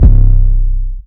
MURDA_808_TRUNK_C.wav